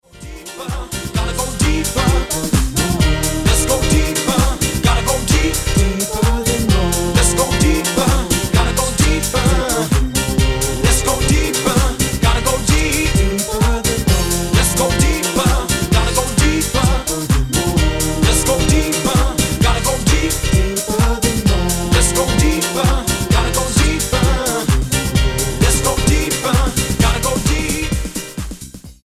house music
Style: Dance/Electronic